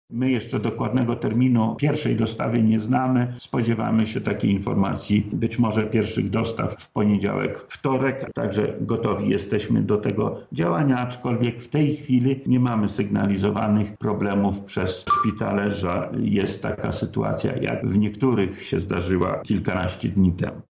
27 listopada rozpoczynają się dostawy tlenu do szpitali w poszczególnych województwach Polski– mówi wojewoda lubelski Lech Sprawka: